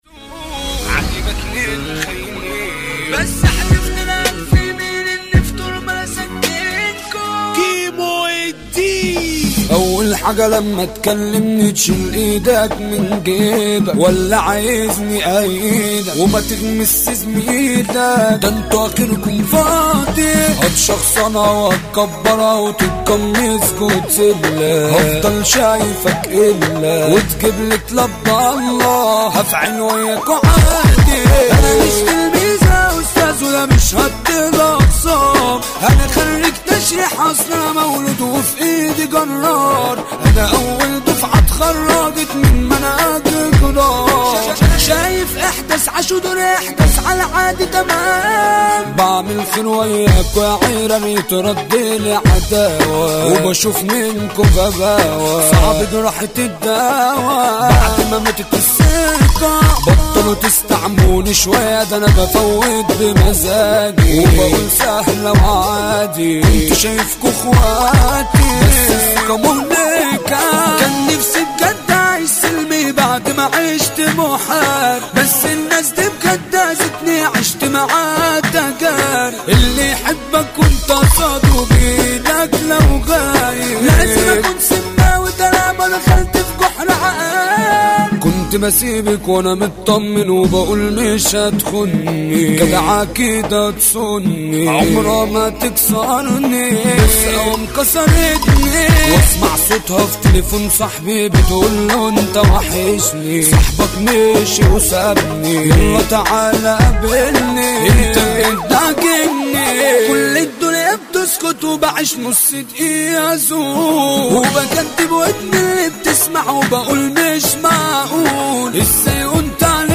اغانى مهرجانات